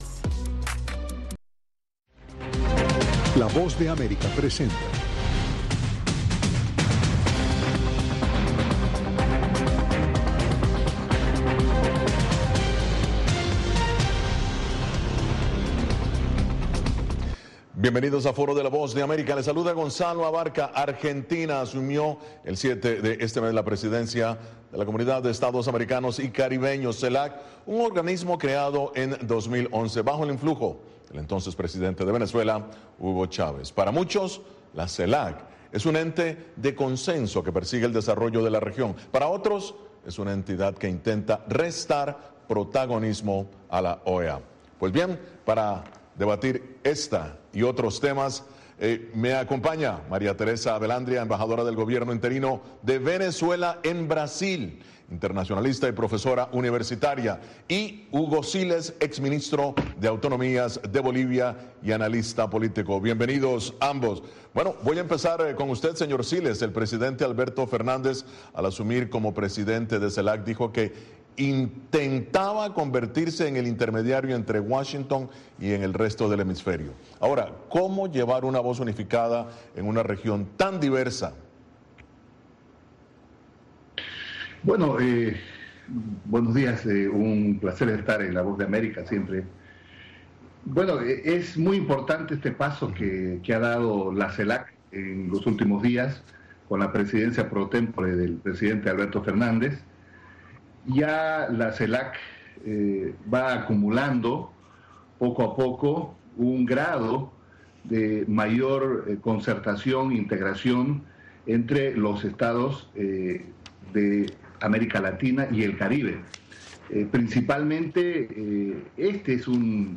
¿Podrá contrarrestar el peso e influencia de la OEA? Debaten Hugo Siles, ex ministro boliviano y la embajadora María Teresa Belandria.